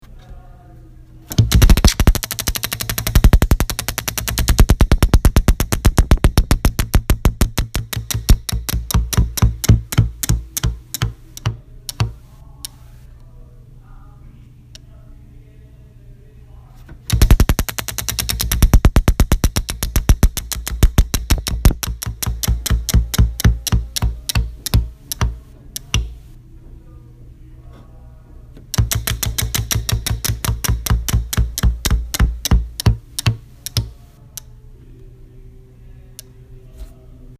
Prize-Wheel.mp3